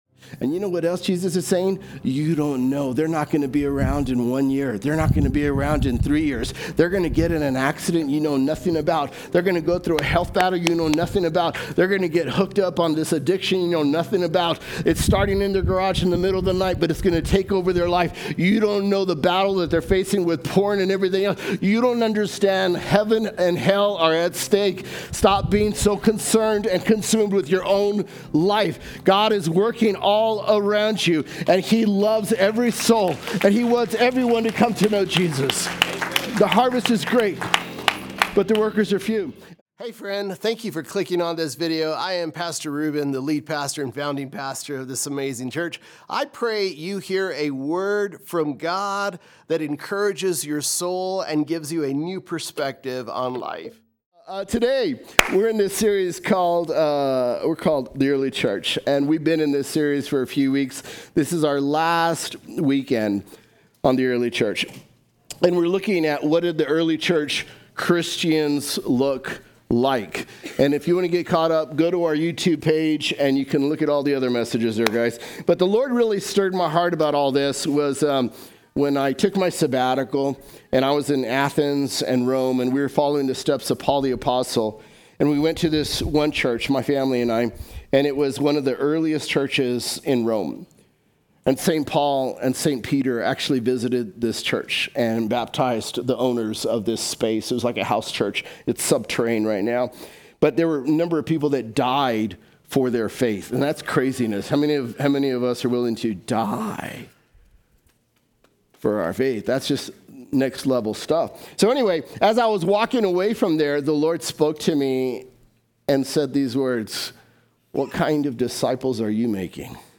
Listen to weekend messages that will inspire, encourage, and help you grow in your faith with Jesus from ThornCreek Church.